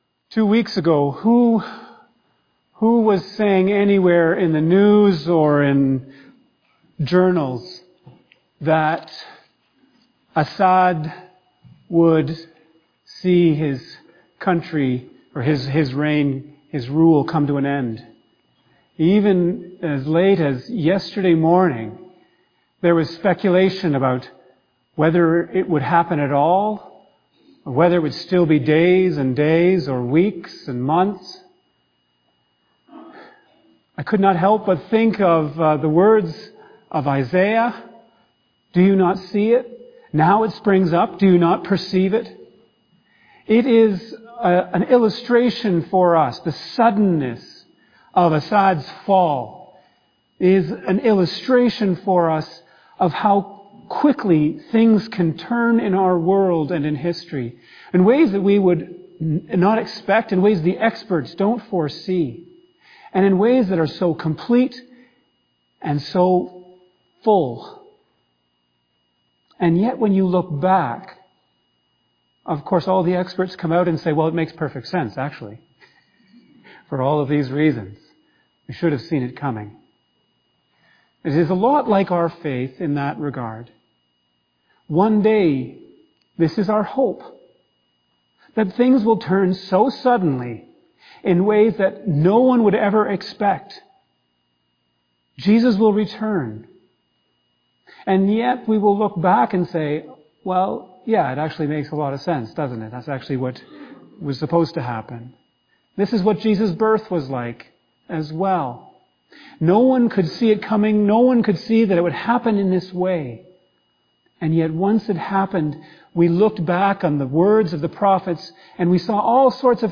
fbcsermon_2024_Dec8.mp3